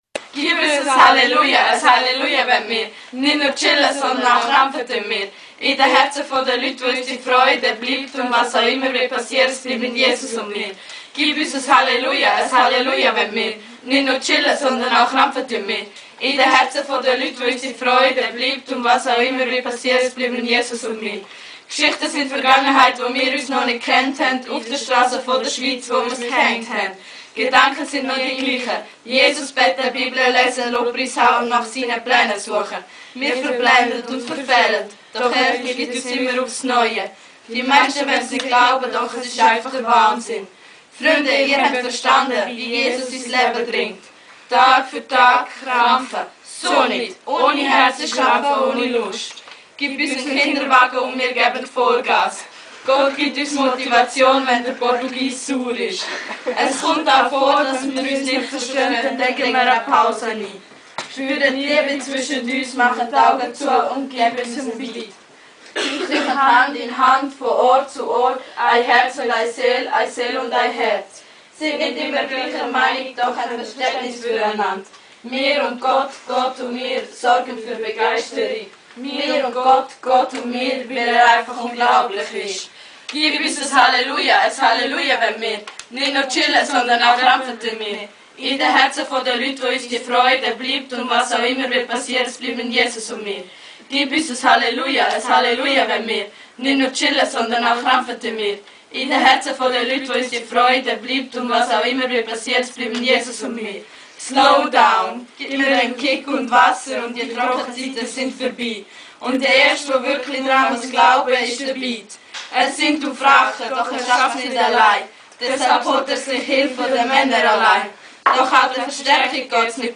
Portugalrap2010.mp3